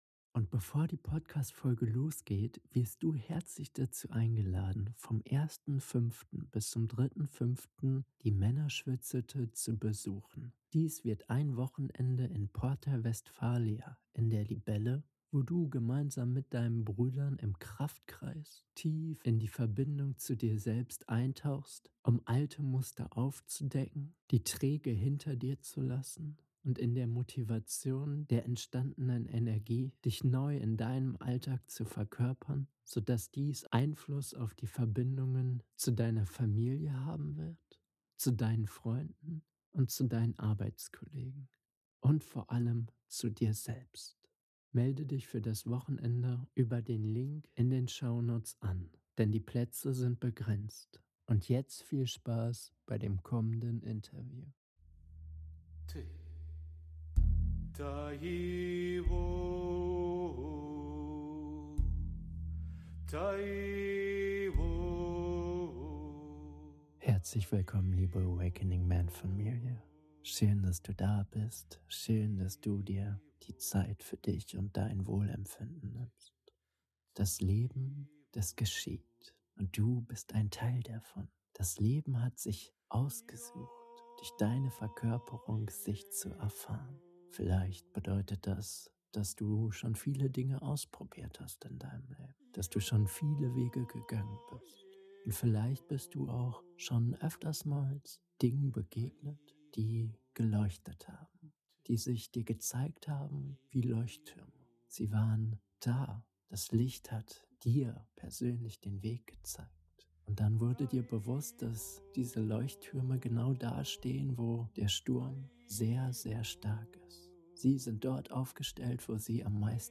Werde zum Leuchtturm - Interview